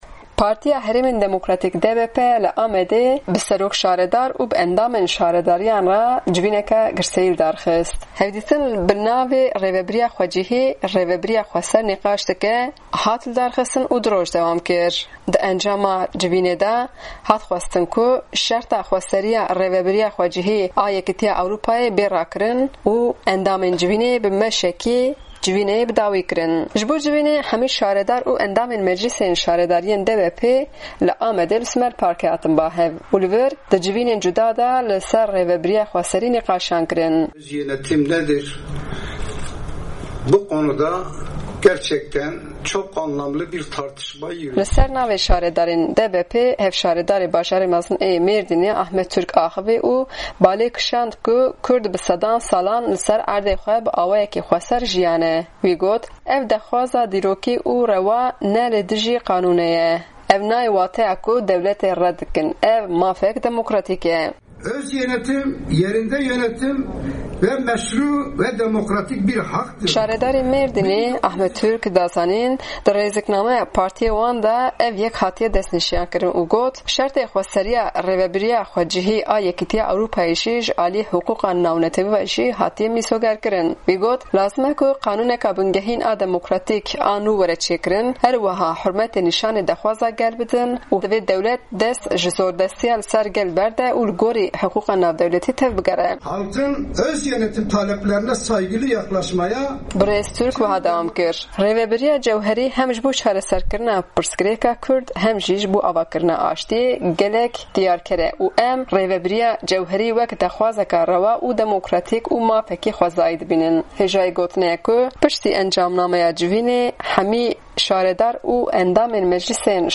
Li ser navê şaredarên DBP'ê Hevşaredarê Bajarê Mezin ê Mêrdînê Ahmet Turk axivî.